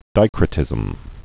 (dīkrə-tĭzəm)